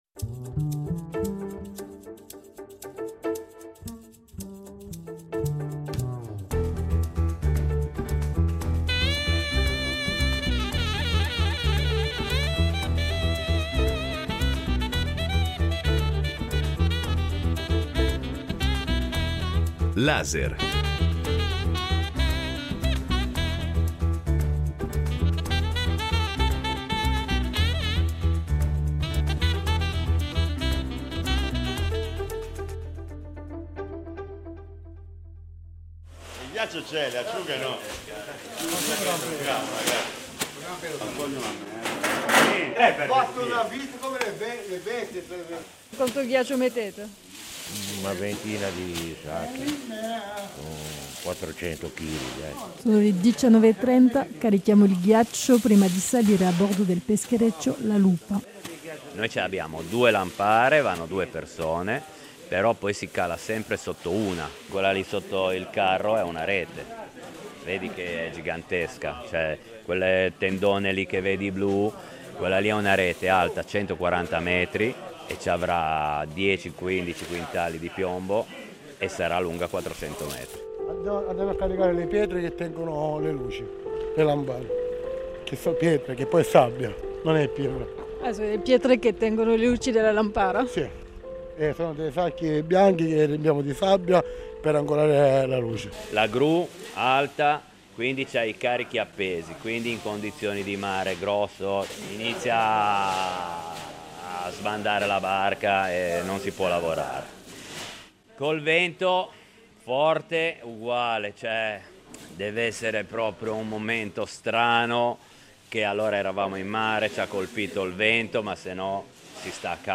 L’audio-documentario immersivo che indaga il mondo della pesca contemporanea
Le voci dei pescatori raccontano le sfide quotidiane e la tecnica della pesca alla lampara. Suoni in presa diretta : motori, onde, reti che scorrono accompagnano ogni racconto. Emergono storie di fatica, sapienza e gesti affilati dalla pratica.